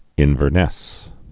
(ĭnvər-nĕs)